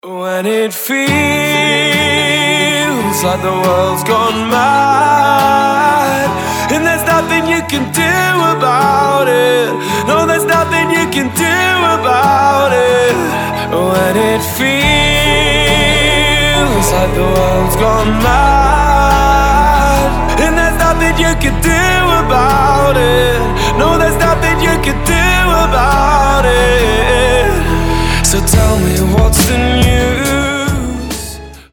• Качество: 320, Stereo
гитара
мужской вокал
красивый мужской голос
спокойные
скрипка
alternative
indie rock
саундтрек